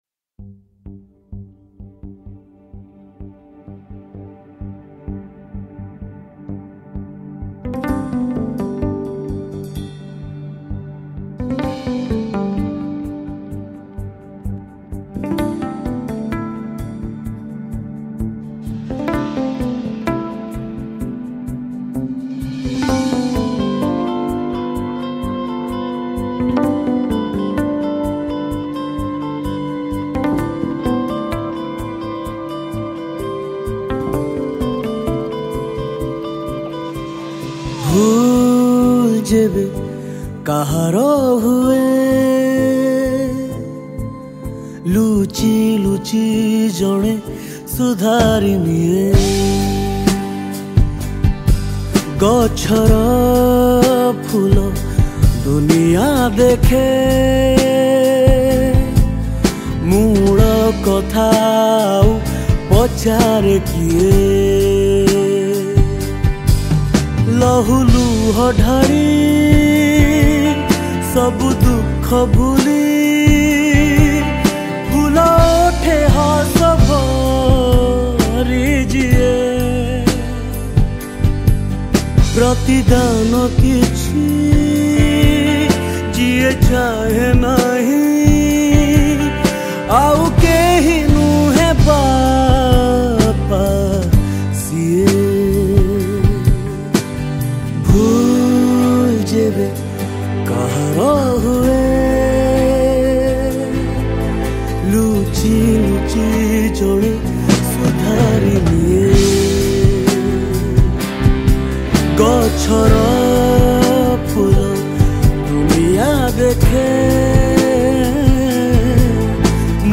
Guitars
Bass
Drums
Backing Vocals Female
Backing Vocals Male